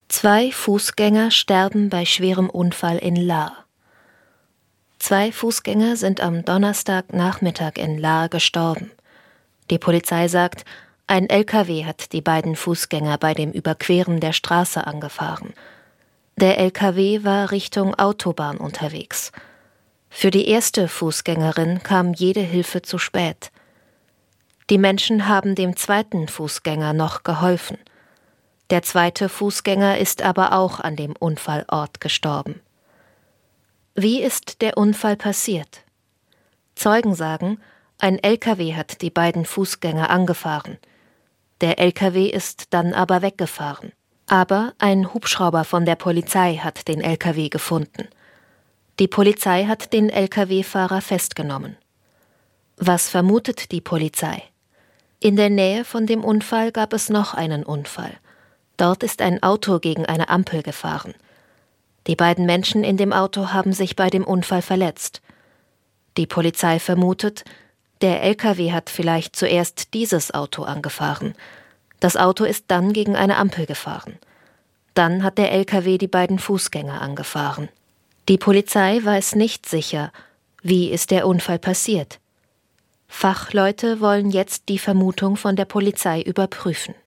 Leichte Sprache | Baden-Württemberg
Nachrichten in Leichter Sprache / Barrierefreiheit